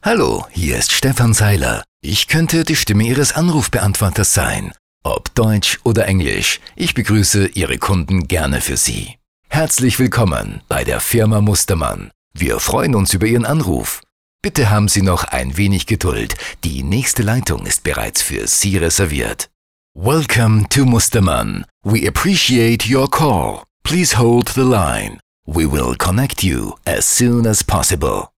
männliche Sprecher - B - soundlarge audioproduktionen